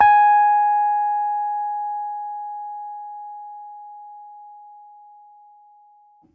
piano-sounds-dev
Rhodes_MK1